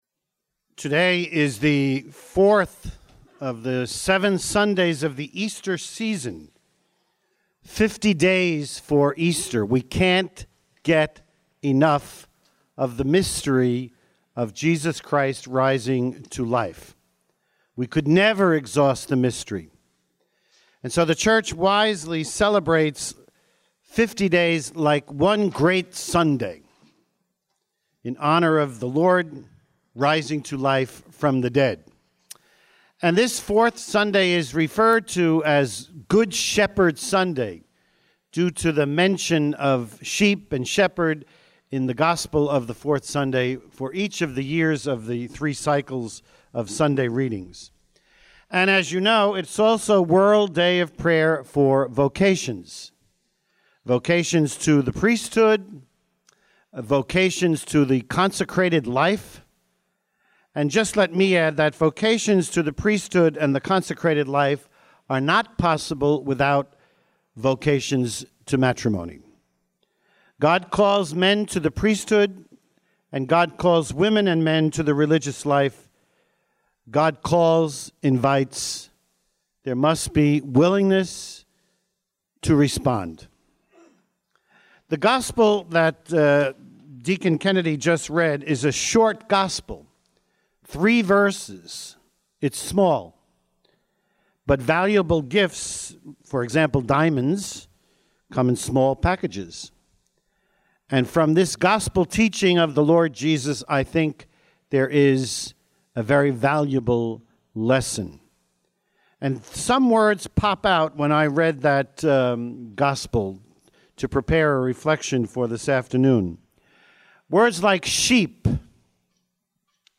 Bishop Sullivan’s iRace4Vocations Homily
2016_iRace_Homily.mp3